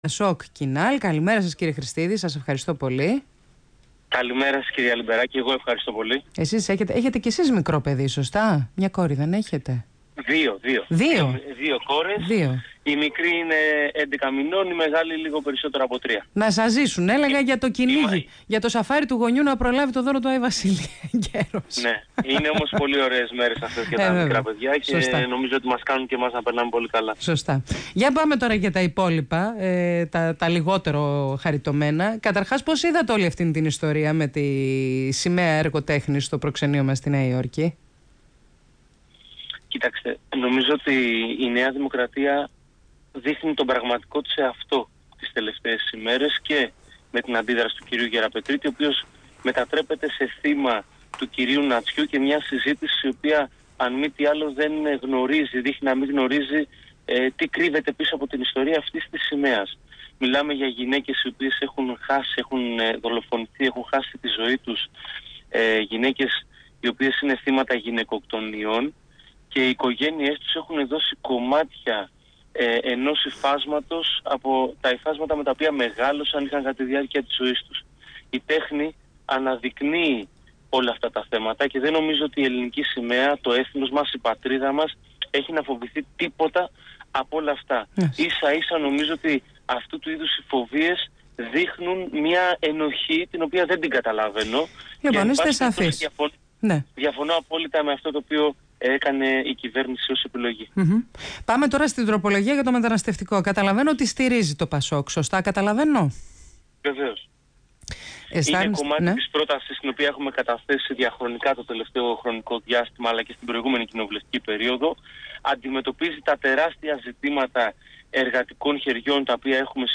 Για «καθεστωτική αντίληψη» έκανε λόγο ο Π. Χρηστίδης, μιλώντας στα Παραπολιτικά 90,1 fm